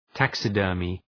{‘tæksı,dɜ:rmı}